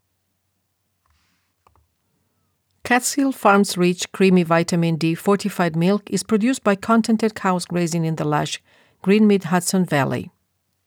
Other than the level is too low, that’s a good recording …
I applied the three tools in Audiobook Mastering and your file passes ACX.